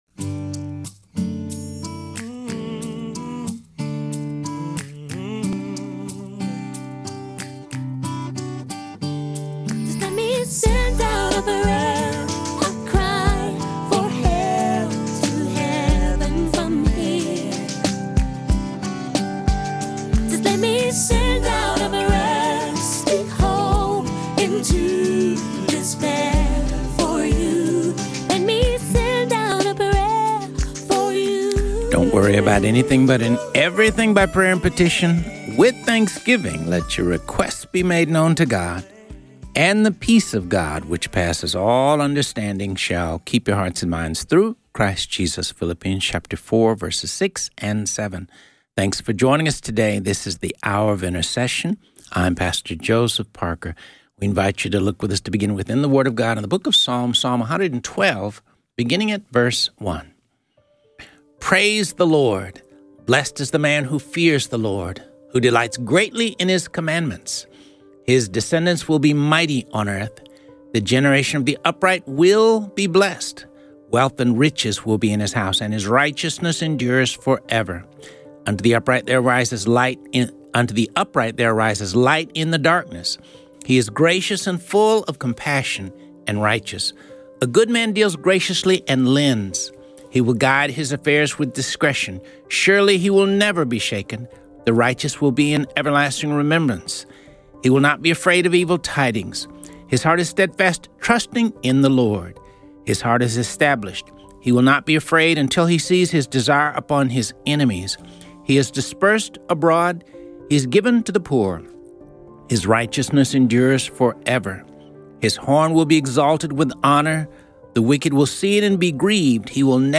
This show covers topics of prayer, intercession, the Word of God and features interviews with pastors and religious leaders.